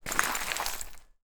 Rocks.wav